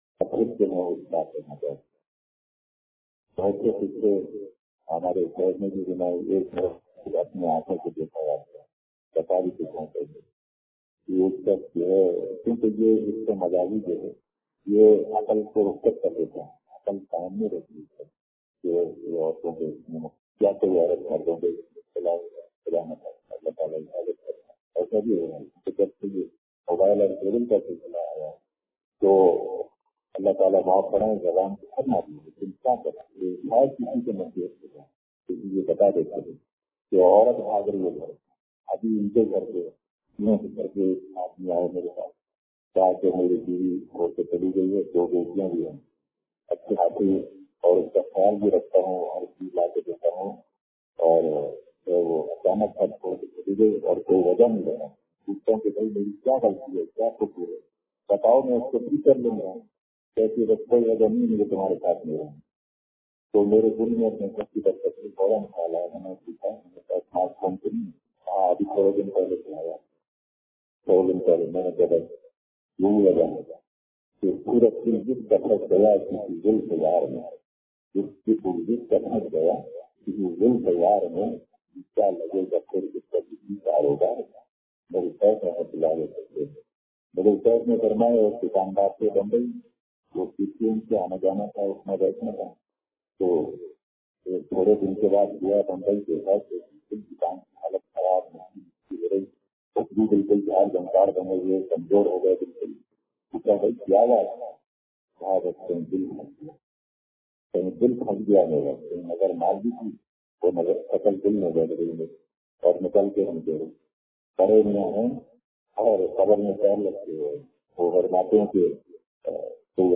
بیان